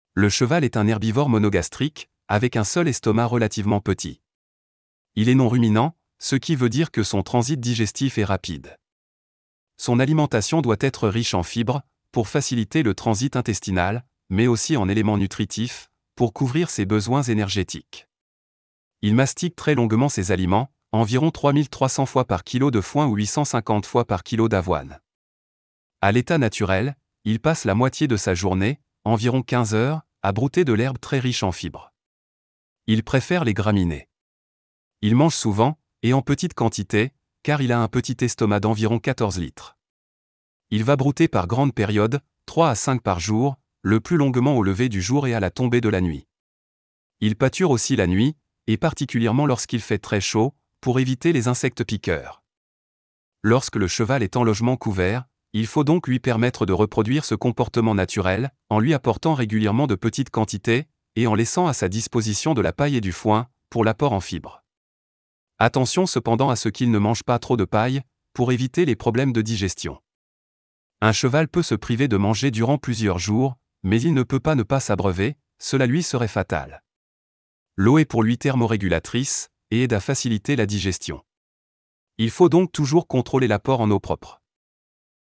Voici l’extrait sur le comportement alimentaire du galop 2. Toutes les voix sont synthétiques afin de garantir un tarif accessibles à tous.